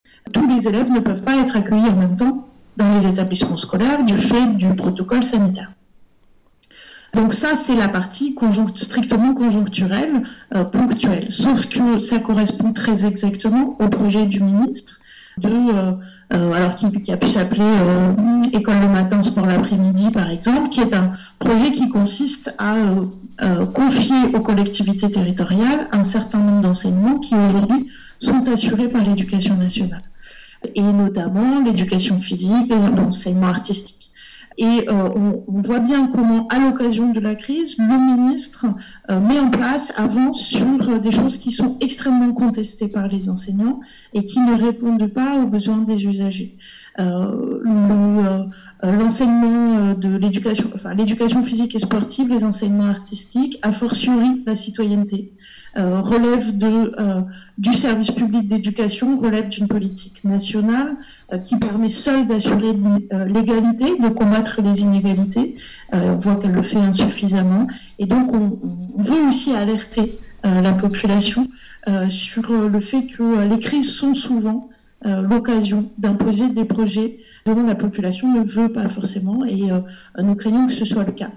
Entretiens.